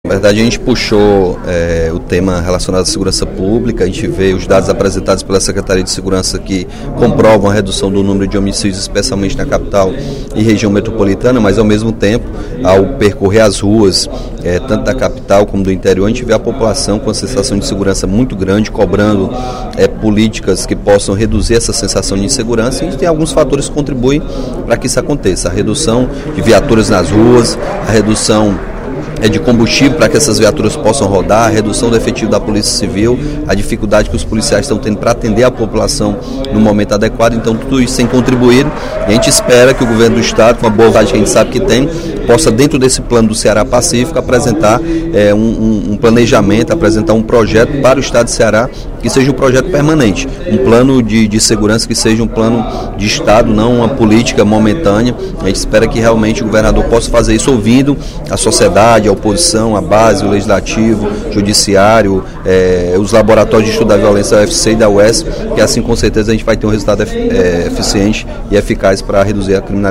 O deputado Capitão Wagner (PR) lamentou, em pronunciamento no primeiro expediente da sessão plenária desta terça-feira (23/06), o crescimento da sensação de insegurança da população cearense.